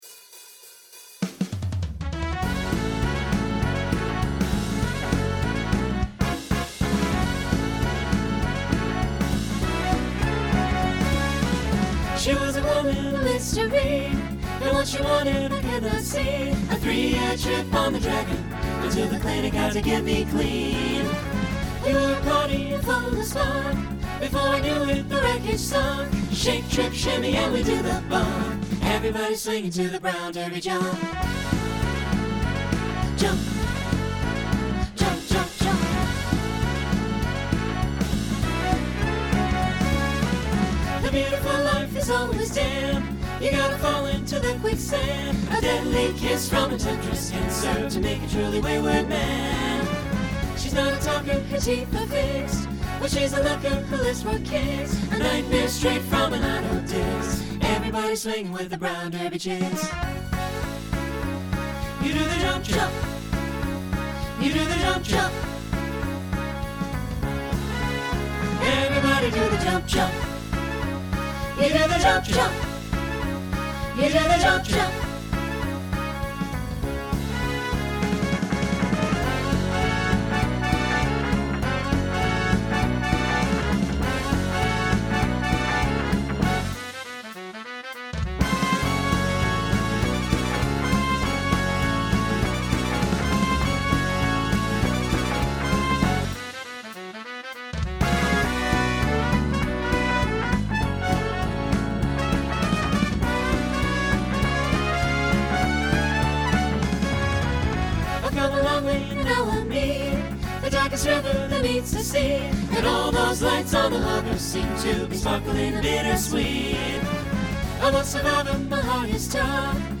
Genre Swing/Jazz
Transition Voicing SATB